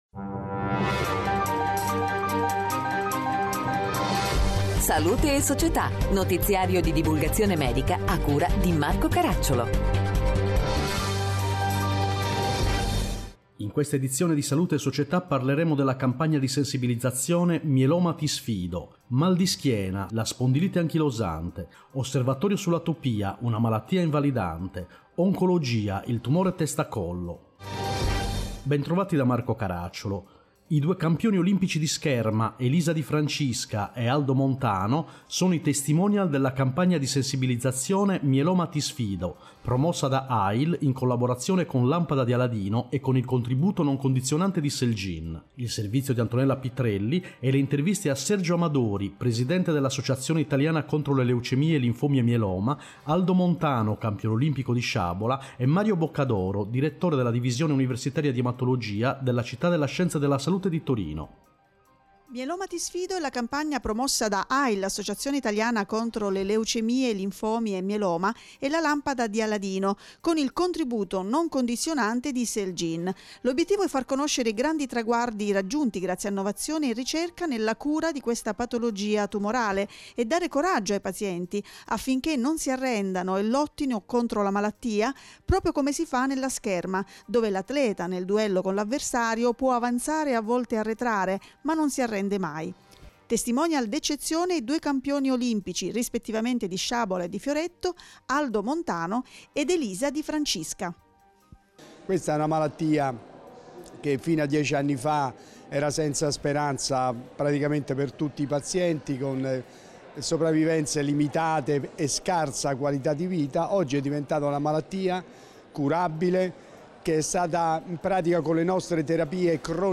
In questa edizione: 1. Sensibilizzazione, Mieloma ti Sfido 2. Mal di schiena, Spondilite anchilosante 3. Osservatorio sull’Atopia, Una malattia invalidante 4. Oncologia, Tumore testa-collo Interviste